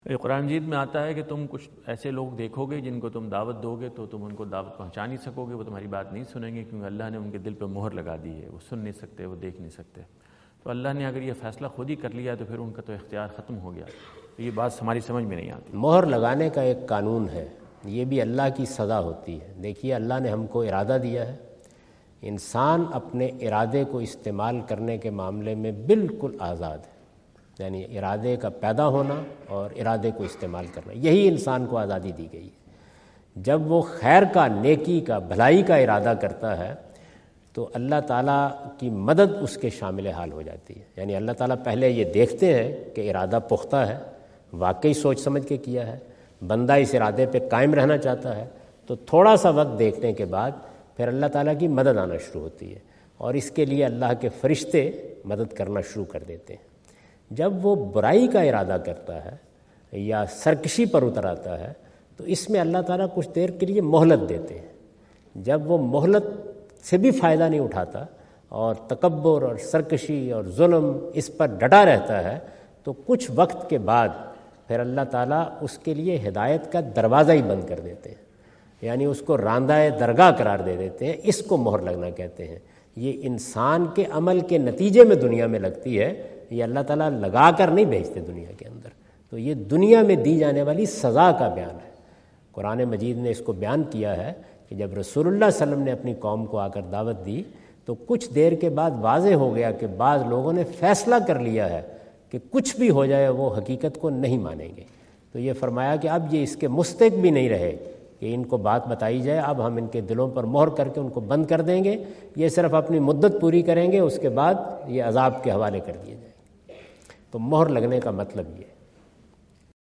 Javed Ahmad Ghamidi answer the question about "Sealed Hearts of Disbelievers" asked at North Brunswick High School, New Jersey on September 29,2017.
جاوید احمد غامدی اپنے دورہ امریکہ 2017 کے دوران نیوجرسی میں "اللہ پر ایمان نہ رکھنے والوں کے دلوں پر مہر" سے متعلق ایک سوال کا جواب دے رہے ہیں۔